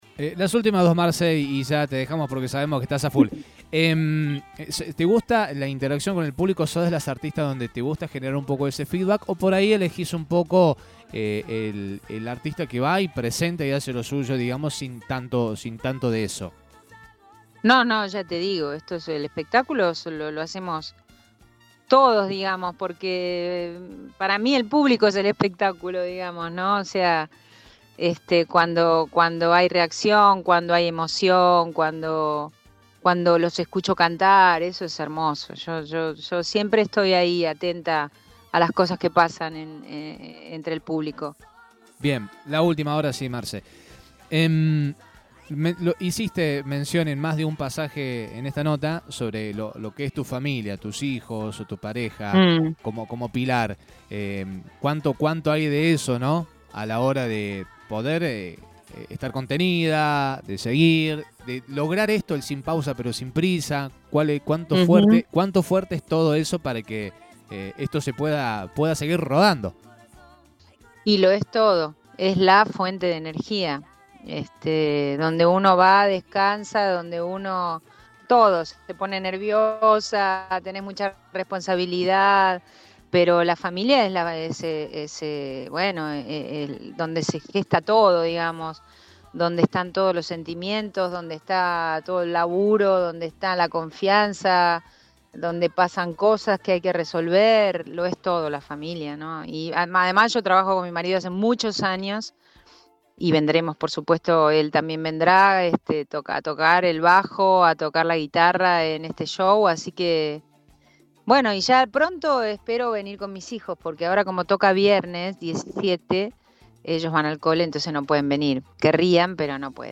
A modo de despedida, Marcela extendió una cálida invitación a sus admiradores para que formen parte de su próximo espectáculo, cerrando la nota con el tema “Los Amantes”.